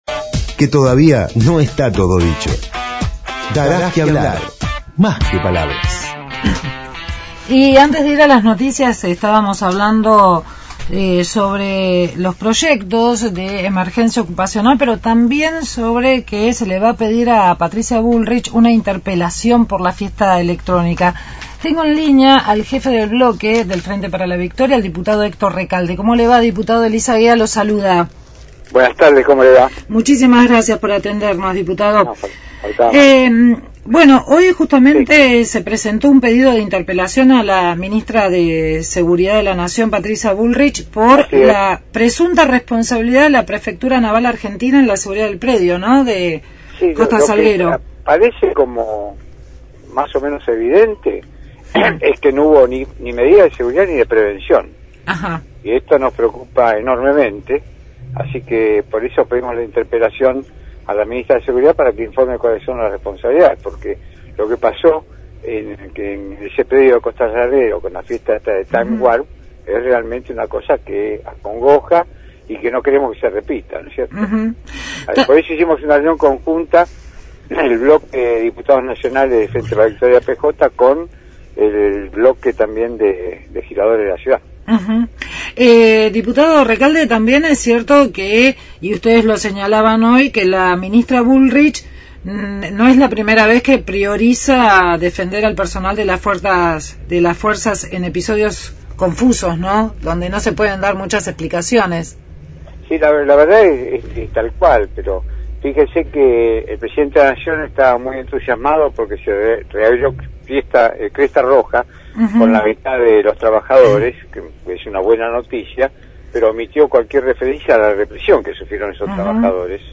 Entrevista a Héctor Recalde, presidente del bloque de Diputados del Frente para la Victoria de la bancada del FpV acerca de la presentación en el Congreso de un pedido de interpelación a la Ministra de Seguridad Patricia Bullrich por la presunta responsabilidad de la Prefectura en la seguridad del predio de Costa Salguero, donde se desarrolló la fiesta electrónica Time Warp.